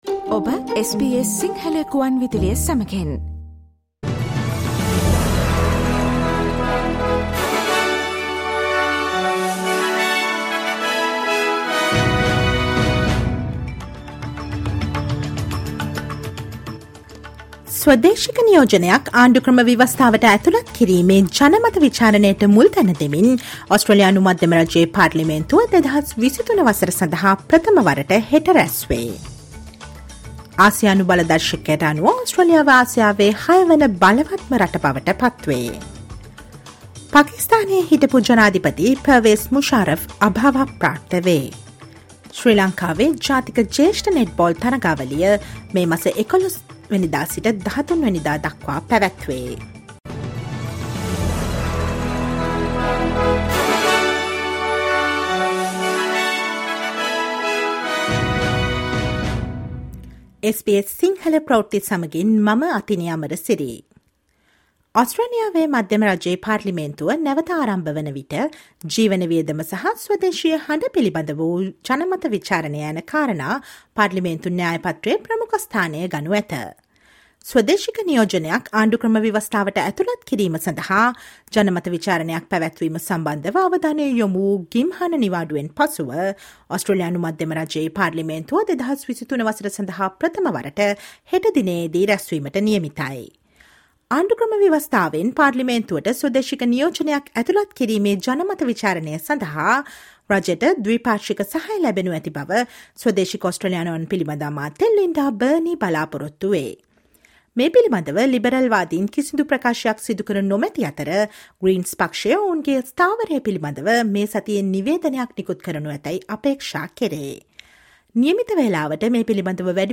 Australia's strong defence networks made it sixth most powerful nation in Asia: News in Sinhala on 06 Feb
Listen to the latest news from Australia, fro Si Lanka ,across the globe, and the latest sports news on SBS Sinhala radio on Monday, 06 February 2023.